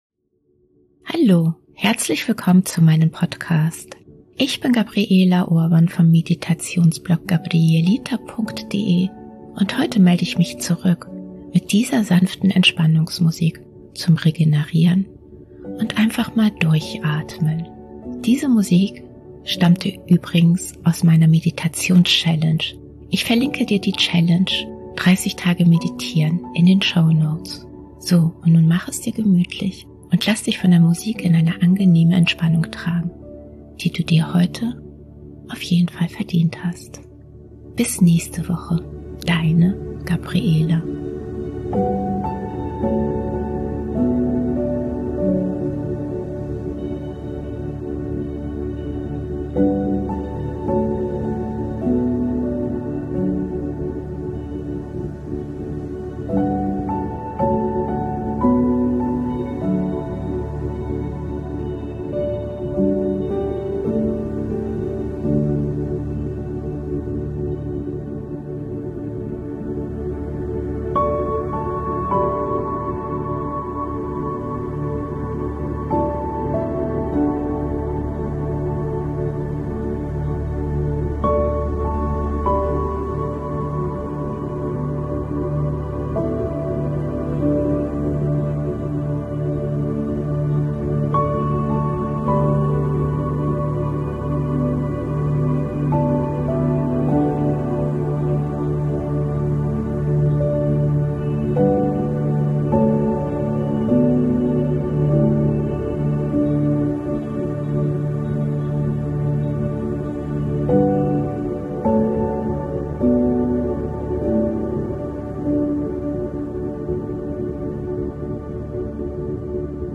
Beschreibung vor 2 Jahren Heute melde ich mich zurück mit dieser sanften Entspannungsmusik zum regenerieren und einfach mal Durchatmen.
Mach es dir gemütlich und lass dich von der regenerierenden Musik in eine wohlige Entspannung tragen, die du dir heute auf jeden Fall verdient hast.